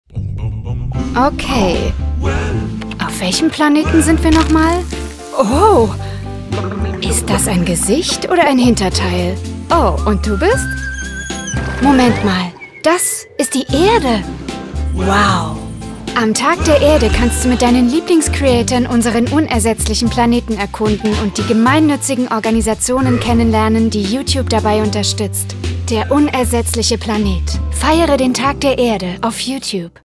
Conversational
Friendly
Accurate